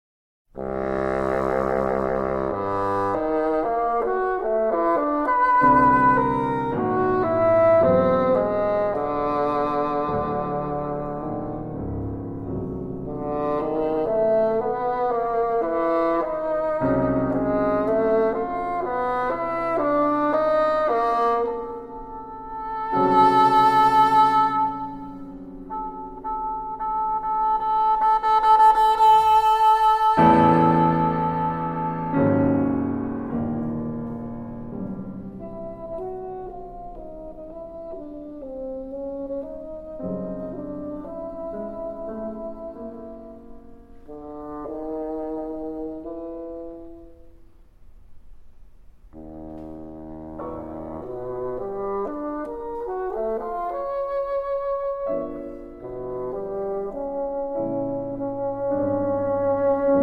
piano and harpsichord